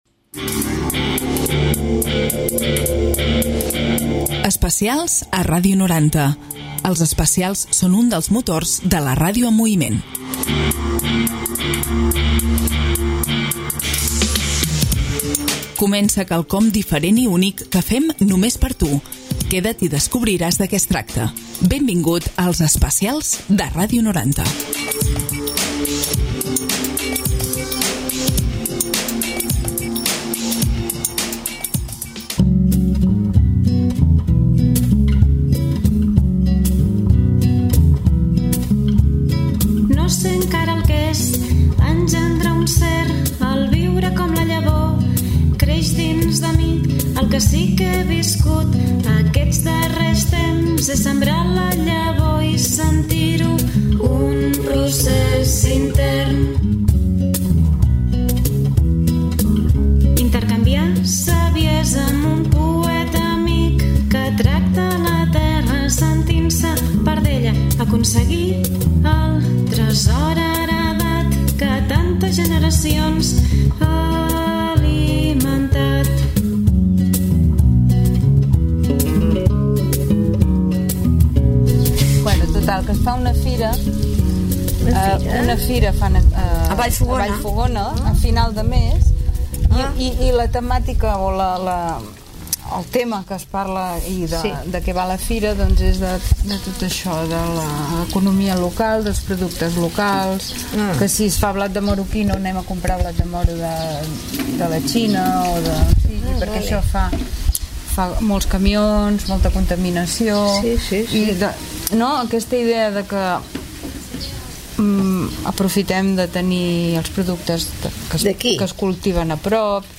I us presentem aquest programa especial on fem un recorregut radiofònic per tota la programació d’aquest festival que ens interpel·la en la nostra manera de viure i de relacionar-nos amb l’entorn i la nostra quotidianitat. En 90 minuts hi posem veu i música per tal d’escoltar el contingut de les activitats, actes, projectes, xerrades, tallers, taules rodones, exposicions, concerts….